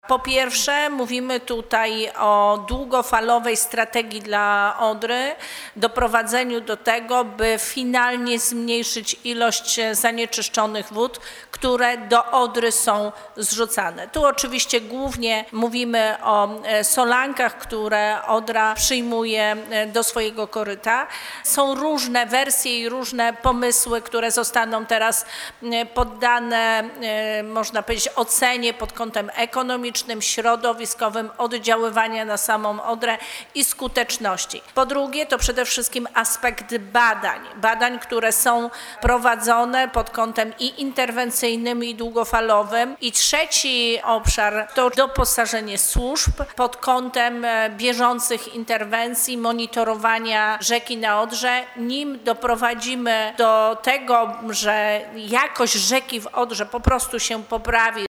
W Dolnośląskim Urzędzie Wojewódzkim odbyła się we wtorek debata Ministerstwa Klimatu i Środowiska z przedstawicielami samorządów oraz podmiotami publicznymi nt. współpracy w zakresie zapobiegania zanieczyszczeniom w rzece Odrze.